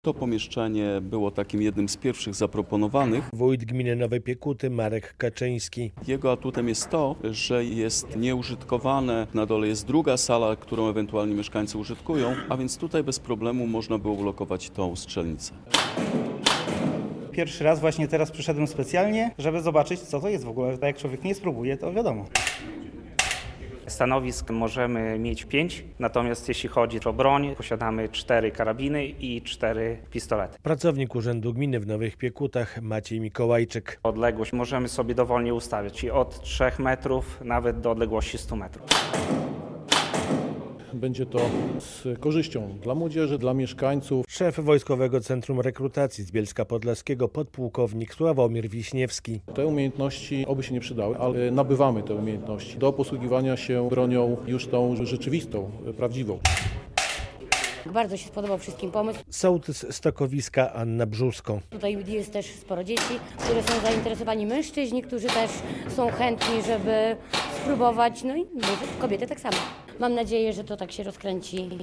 Wirtualna strzelnica w Stokowisku już otwarta - relacja